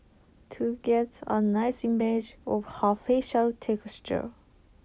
Interspeech2020_Accented_English_Speech_Recognition_Competition_Data